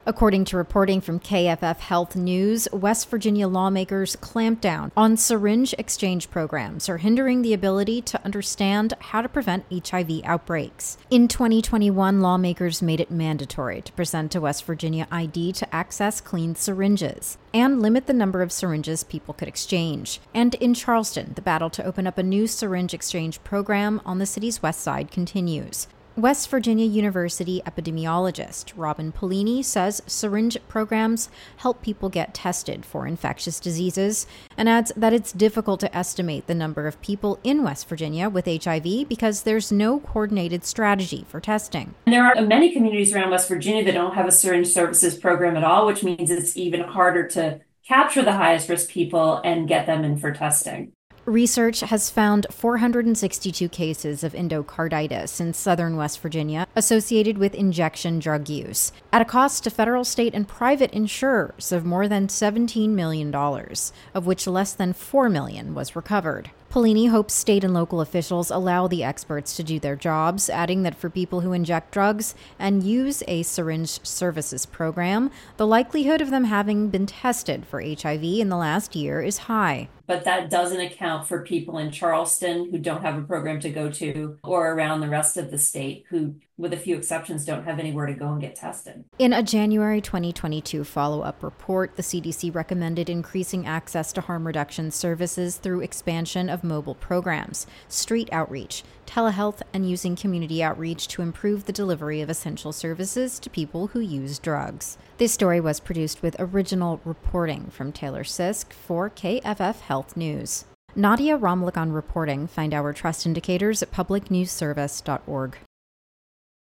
Audio summary